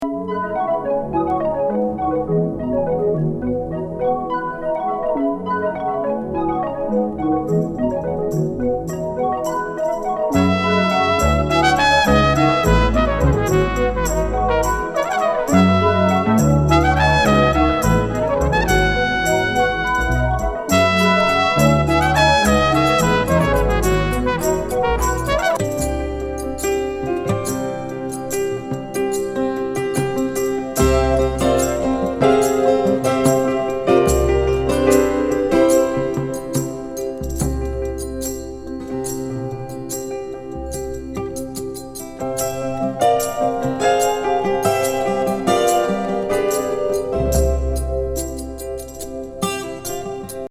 オレゴンのギタリスト/マルチプレイヤー’82年、一人多重録音作！